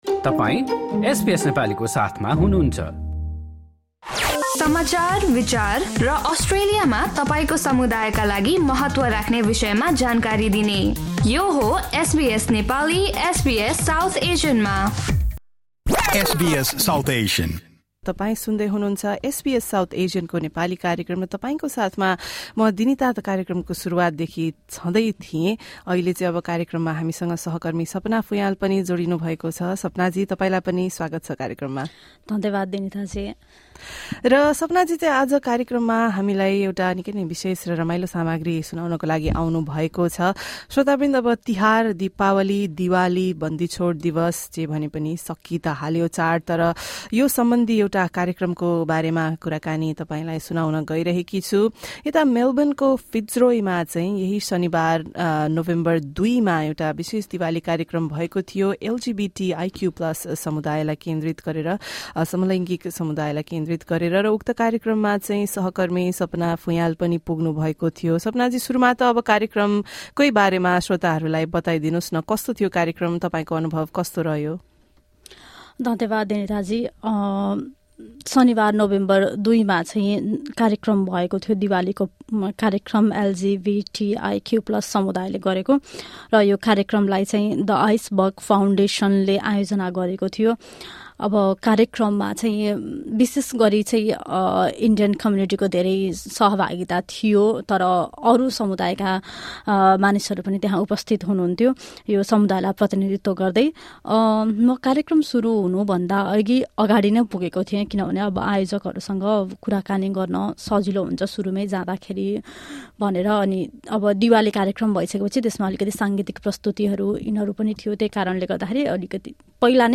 आइसवग फाउन्डेसनले शनिवार दुई नोभेम्बरमा एलजीबीटीआइक्यु प्लस समुदायको दिवाली कार्यक्रम आयोजना गरेको थियो। कार्यक्रममा सहभागी एलजीबीटीआइक्यु प्लस समुदायका सदस्यहरू लगायत उनीहरूको समर्थनमा आएका अलाइहरूले एसबीएस नेपालीसँग गरेको कुराकानी सुन्नुहोस्।